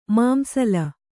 ♪ māmsala